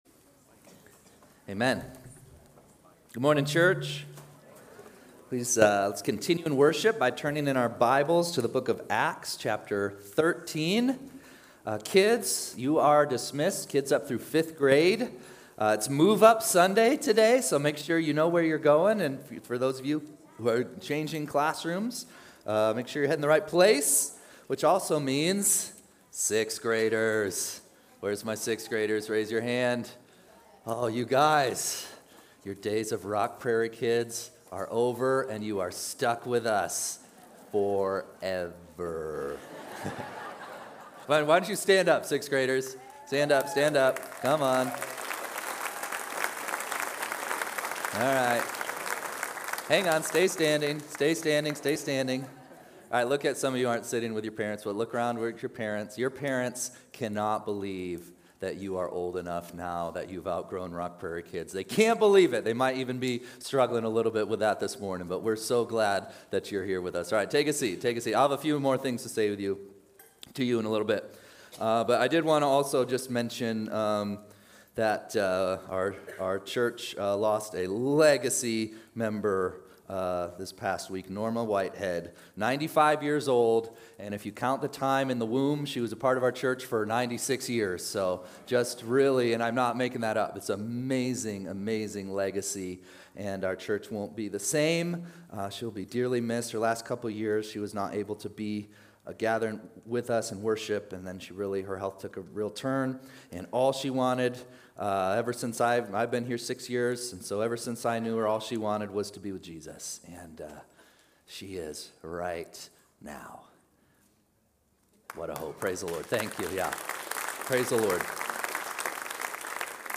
8-10-25-Sunday-service.mp3